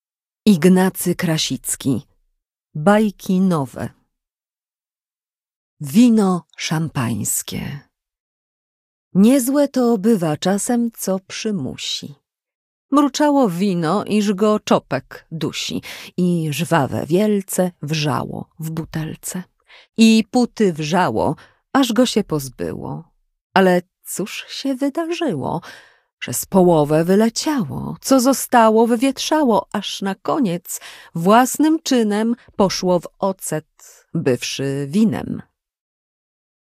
Audiobook